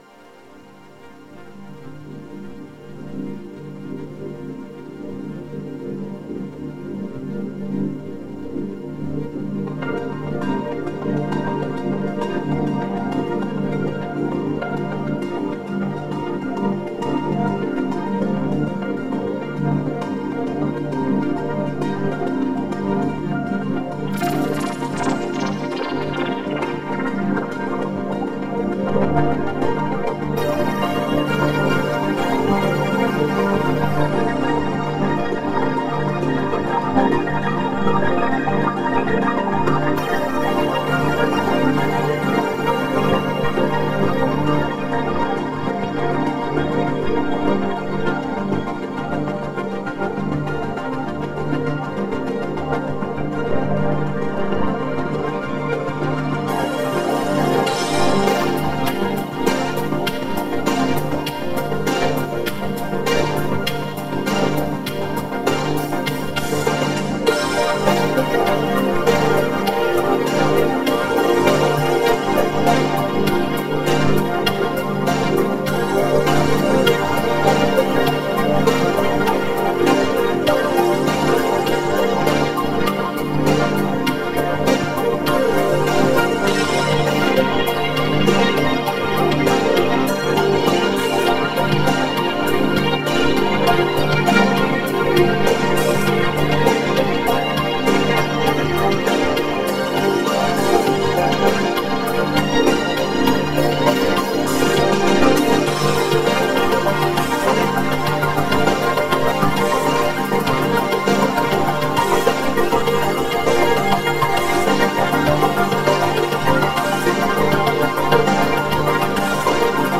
ethereal voices.mp3